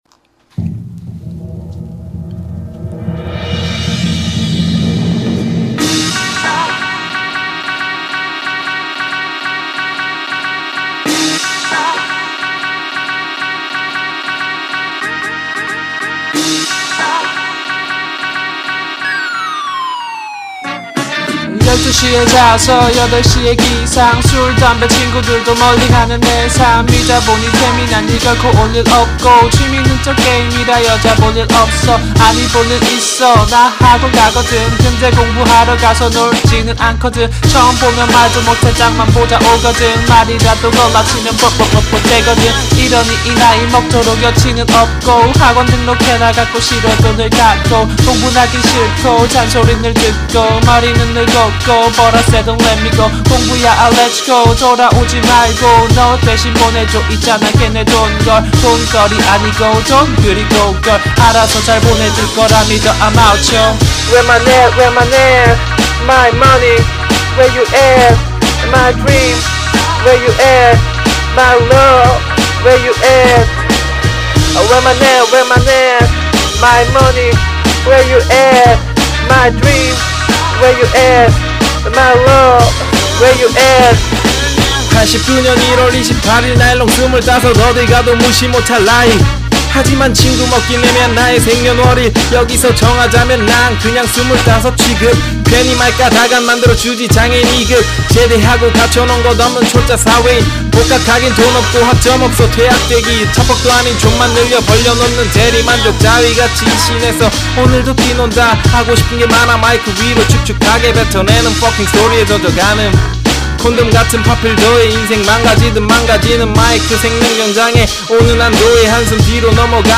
현재 작업중인 랩입니다
친구와 같이 하는 중 입니다 서로 처음 랩 작사 및 레코딩이라 부족한게 많습니다
아직 랩을 할때 있어서 톤,가사 제대로 갈피도 못잡고 있는 상황이구요